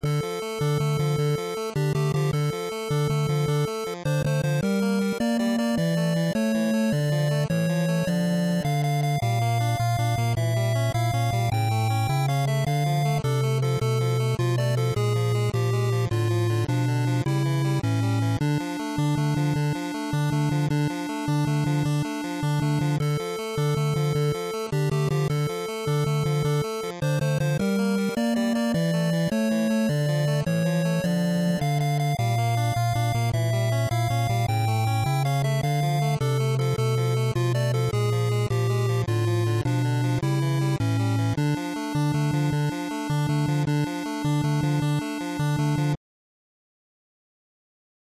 8BitWorldMap2.mp3